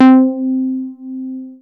MB60 C4.wav